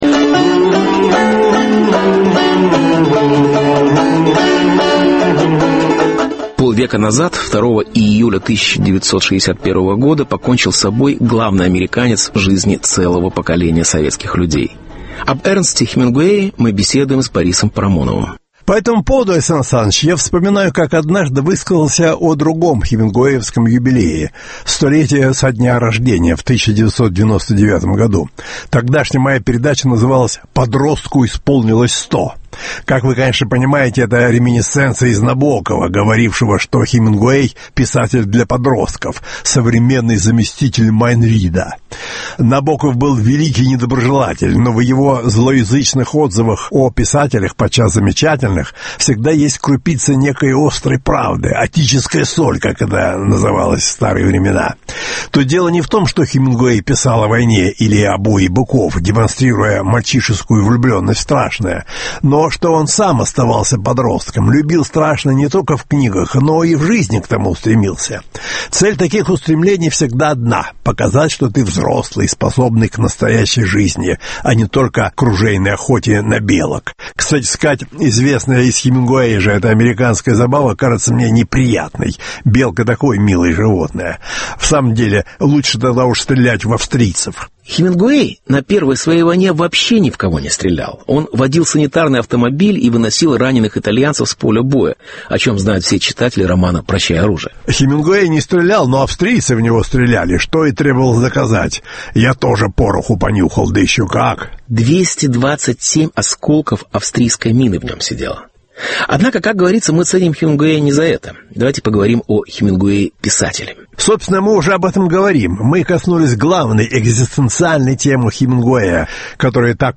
Хемингуэй полвека спустя. Беседа с Борисом Парамоновым.